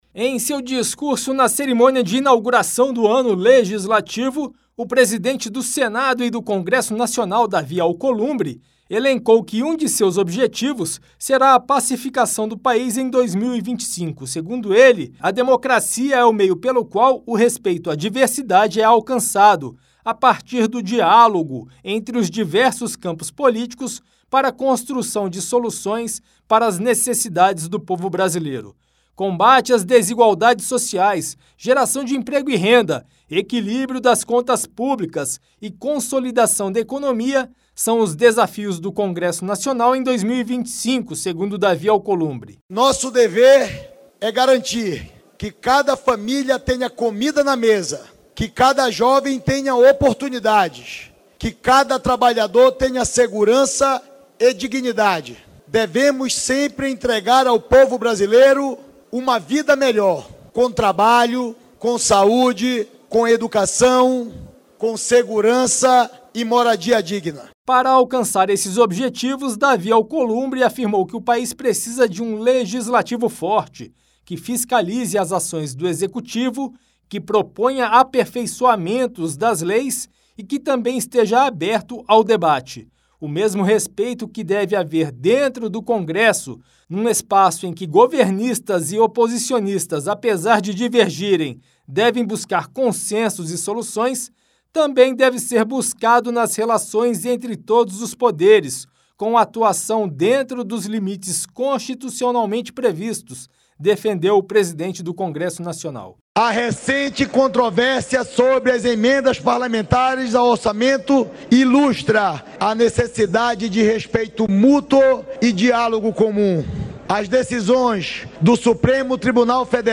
A pacificação do país será um dos principais objetivos para 2025, afirmou o presidente do Senado e do Congresso Nacional, Davi Alcolumbre, em seu discurso na cerimônia de inauguração do ano legislativo, nesta segunda-feira. Ele também destacou outros desafios do Legislativo neste ano, como o combate às desigualdades sociais, a geração de emprego e renda, o equilíbrio das contas públicas e a consolidação da economia.